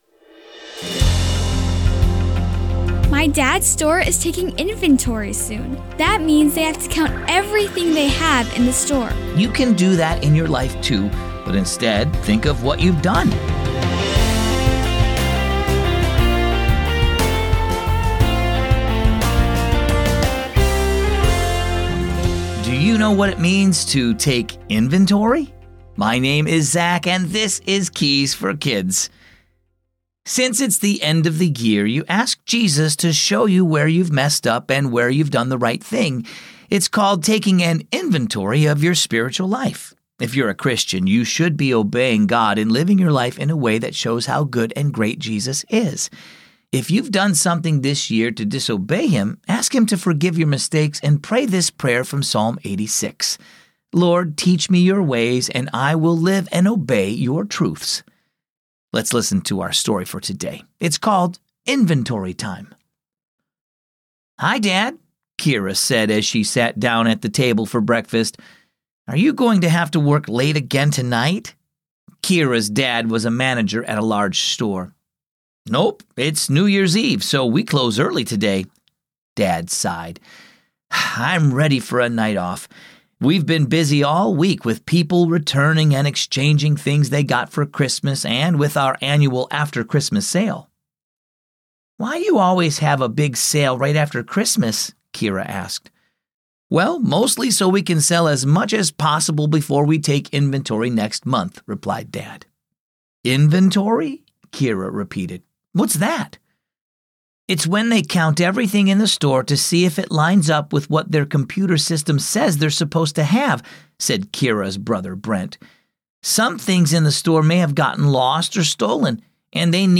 creative voices bringing characters to life